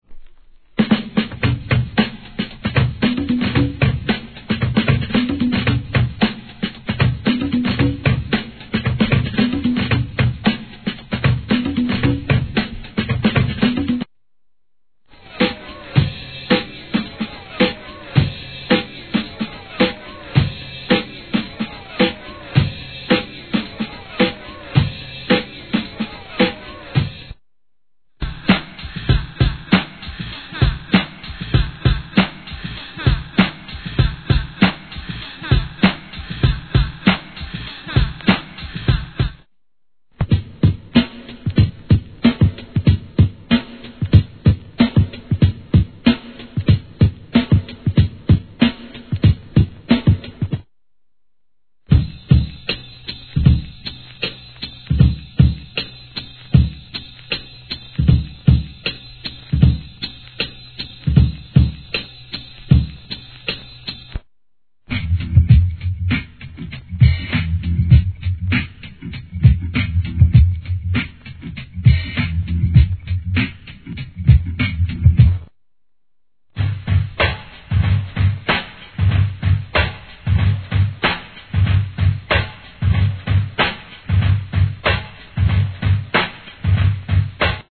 HIP HOP/R&B
BPM 86-113、全13 TRACKS!!